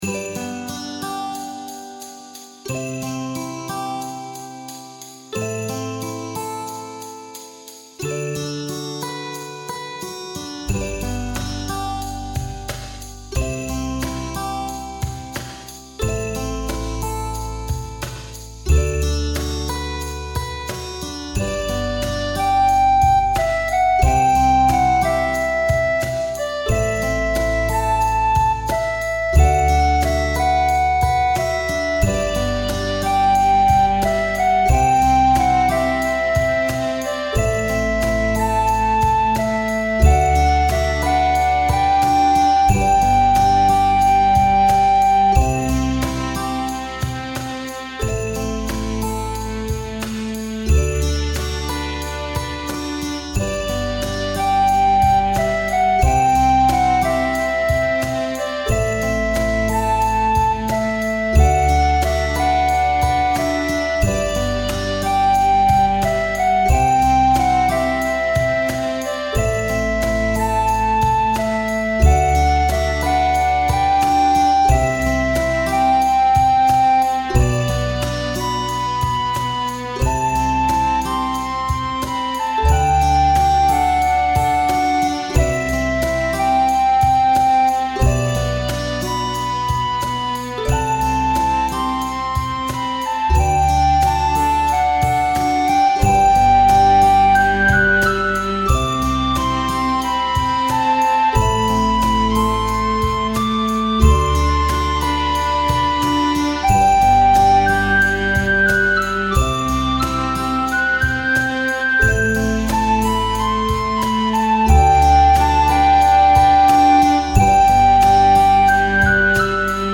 田舎の村周辺にある道を歩いているようなイメージのスローテンポなBGMです。